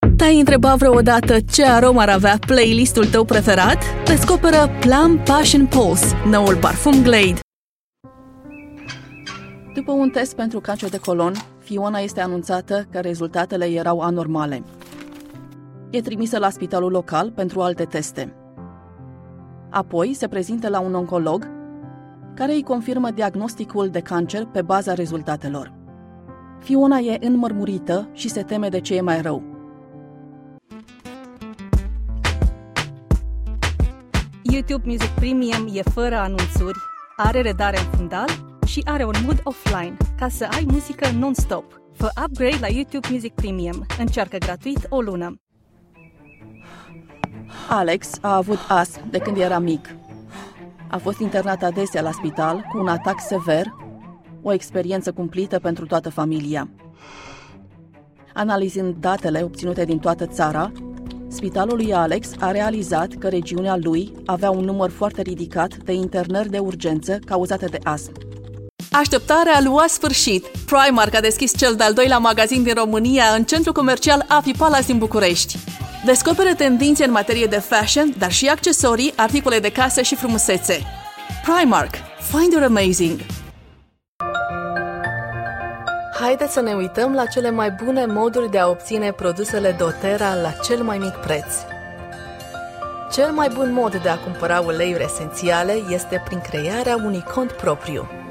Showreel
Female / 20s, 30s, 40s / Romanian Showreel https